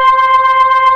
B3 TONE C5.wav